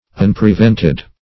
Search Result for " unprevented" : The Collaborative International Dictionary of English v.0.48: Unprevented \Un`pre*vent"ed\, a. 1. Not prevented or hindered; as, unprevented sorrows.